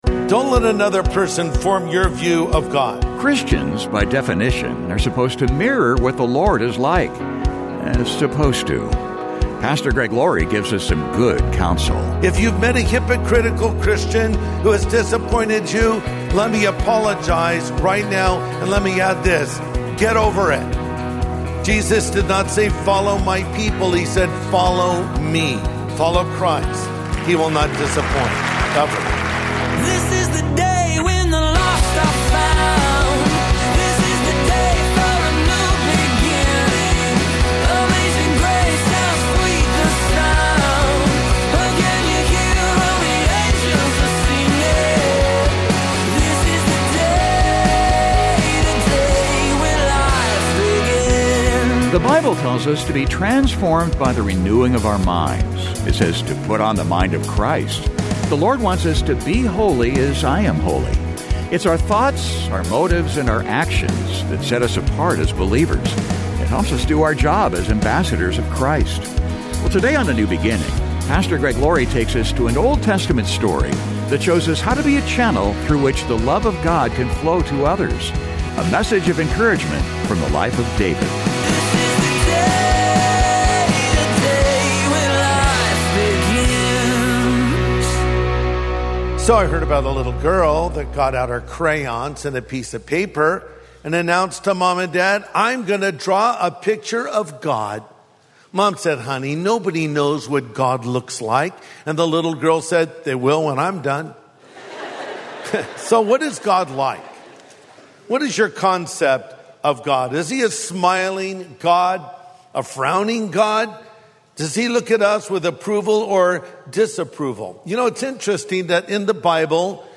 Today on A NEW BEGINNING, Pastor Greg Laurie takes us toÂ an Old Testament story that shows us how to be a channel through which the love ofÂ God can flow to others. A message of encouragement from the life of David.